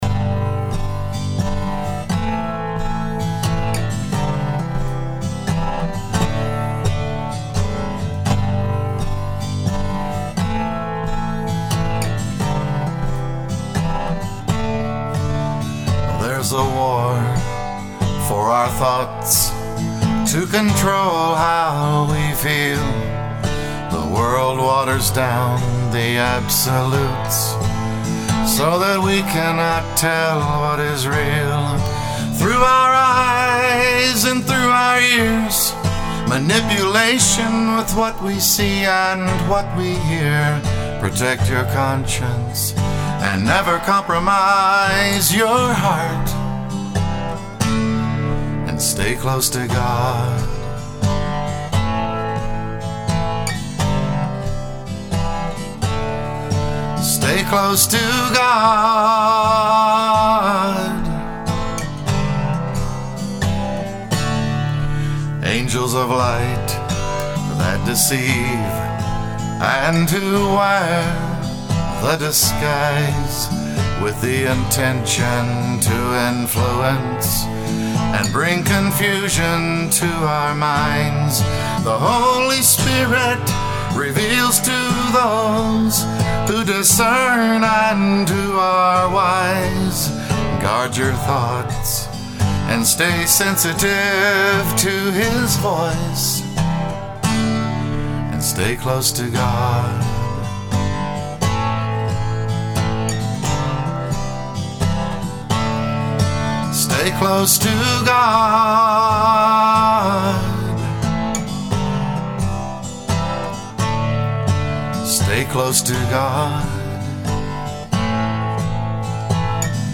This is a brand new CD that we are currently recording.